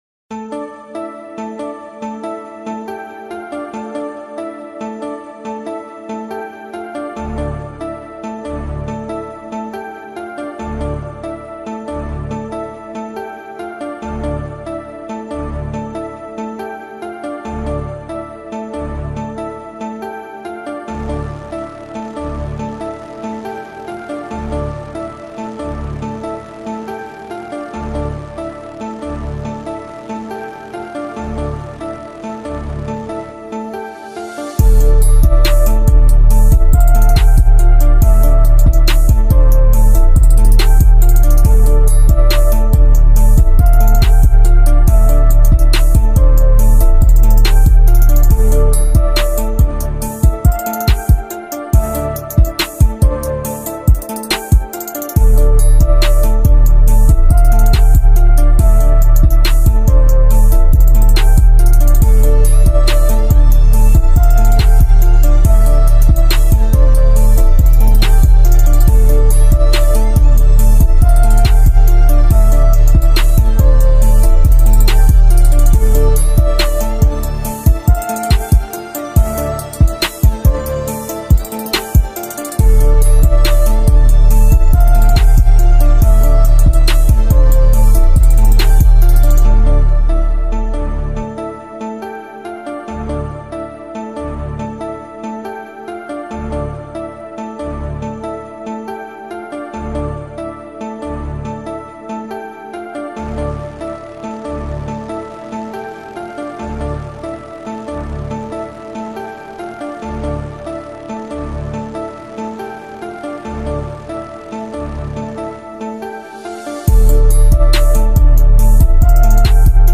Drill Instrumental